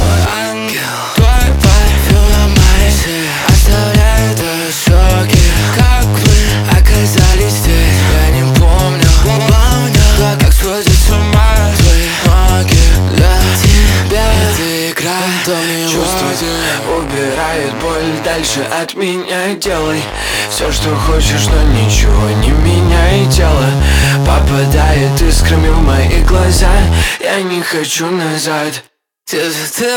Pop Dance